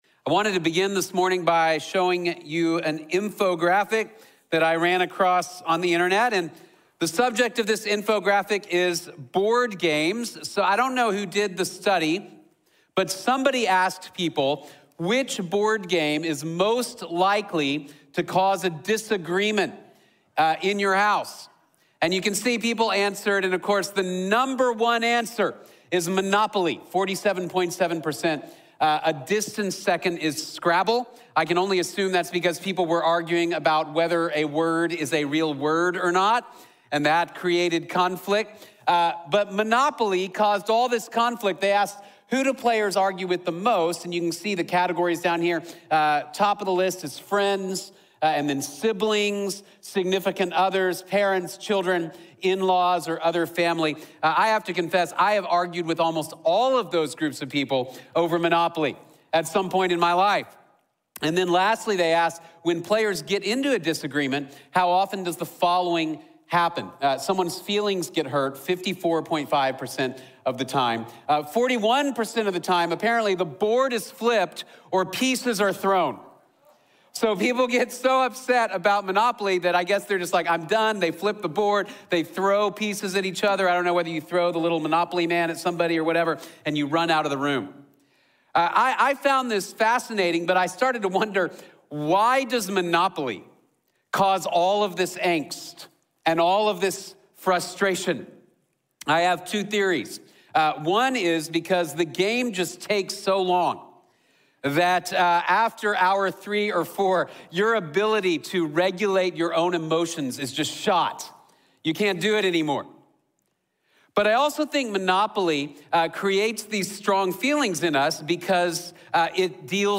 | Sermón de la Iglesia Bíblica de la Gracia